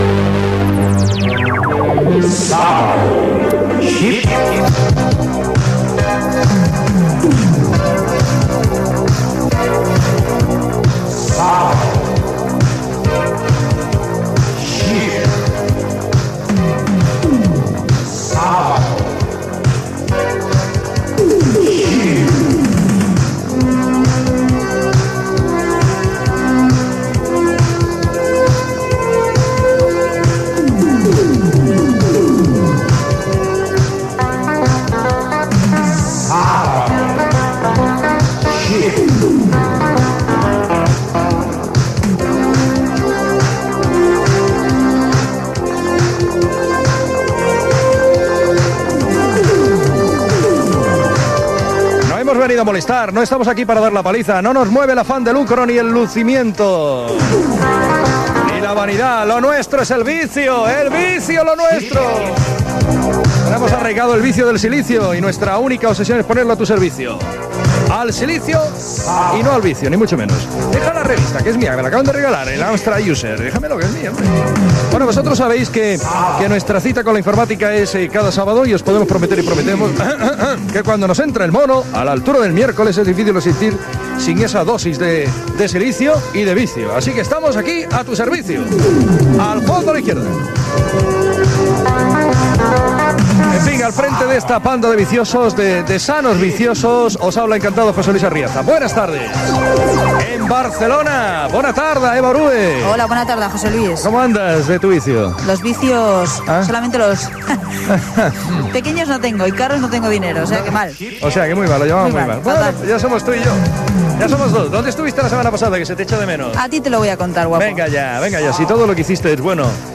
Careta del programa, presentació, equip, telèfon del programa i jocs participatius amb l'audiència (amb la veu de "Chipito"), indicatiu, publicitat, promició del programa "Siempre en domingo", indicatiu i notícies informàtiques.
Gènere radiofònic Entreteniment